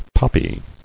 [pop·py]